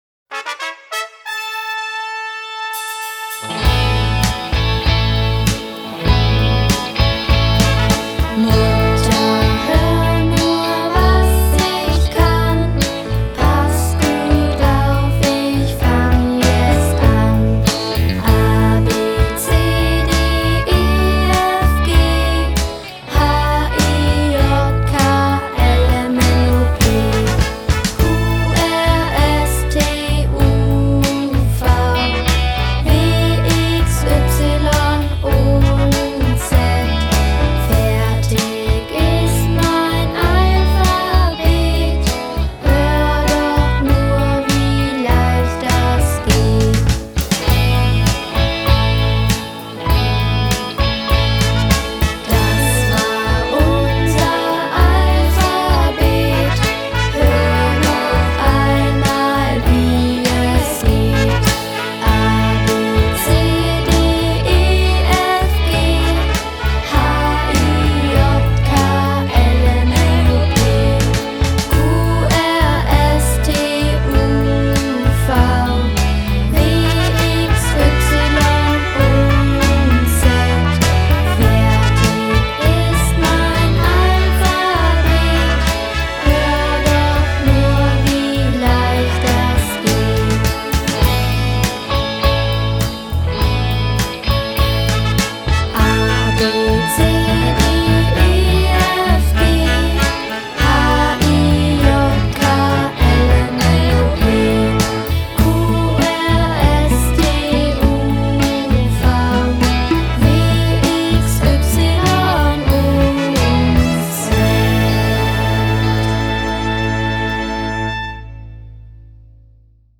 Lernlieder